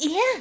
toadette_hrmm.ogg